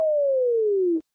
arc_reduce_short.ogg